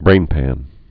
(brānpăn)